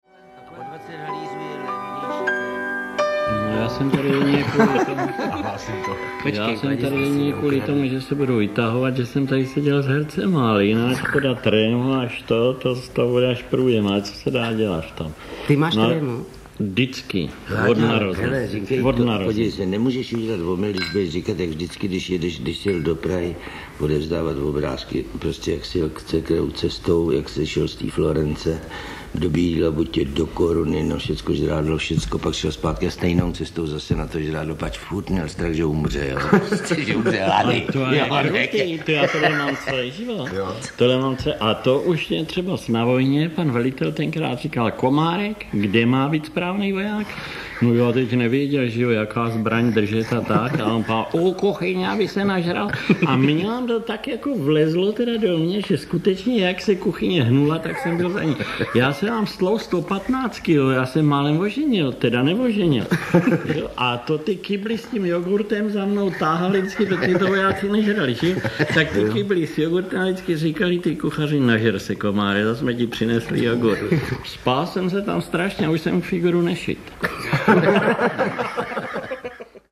Nejedná se o studiovou nahrávku.
• InterpretBoris Rösner, František Němec, Josef Vinklář, Vladimír Komárek, Jiří Štěpnička, Václav Knop